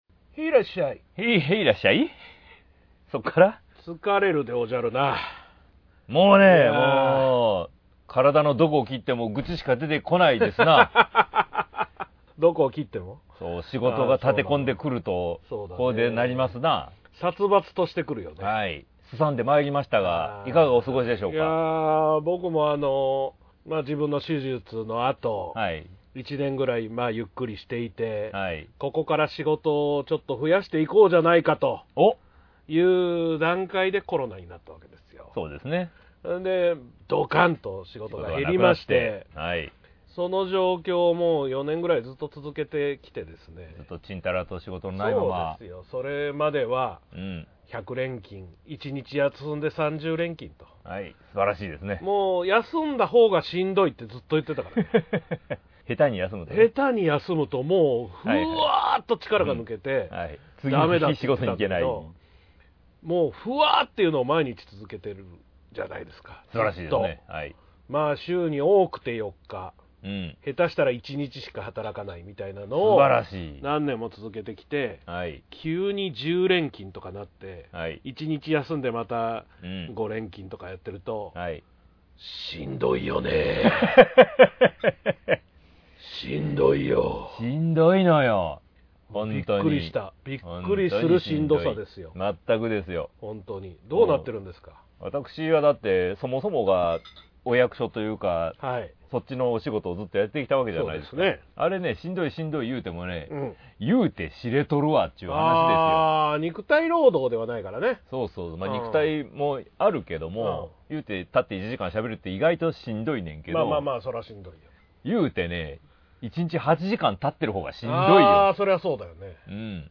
突然のマイクトラブル